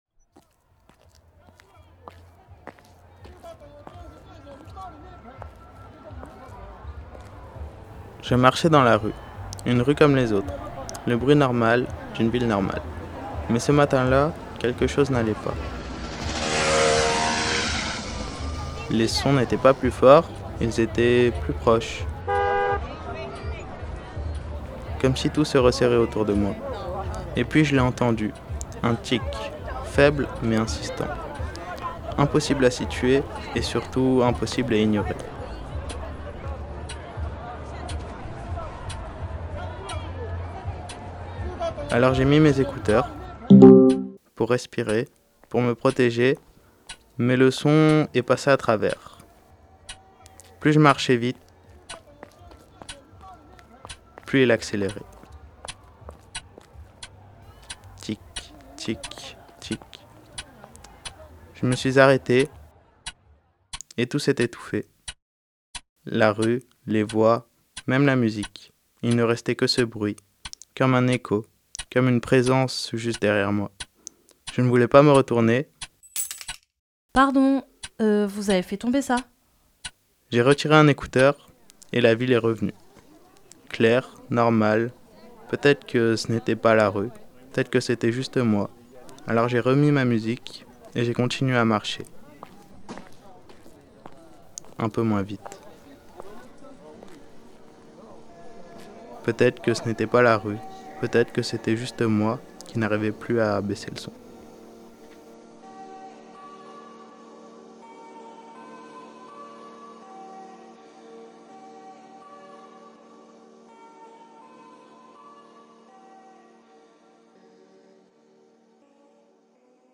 À travers un travail minutieux sur la stéréo et les filtres fréquentiels, le récit explore cette frontière fragile où le monde extérieur devient menaçant.